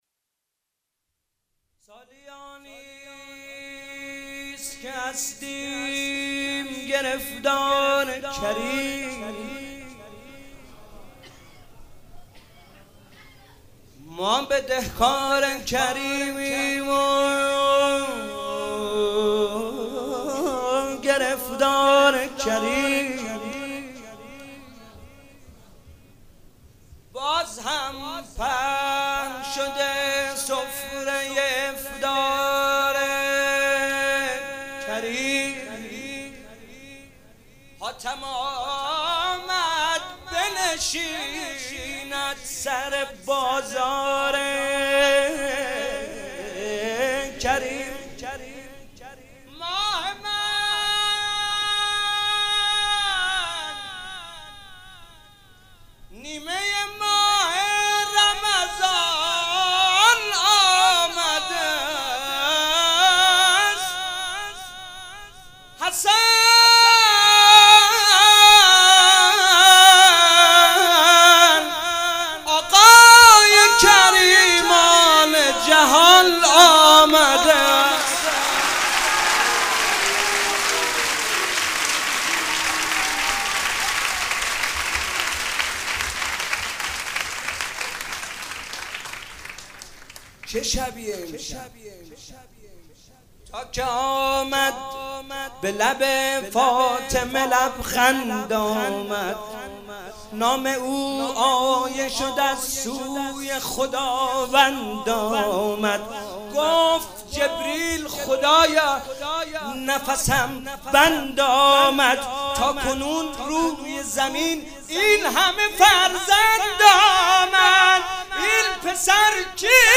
مدح امام حسن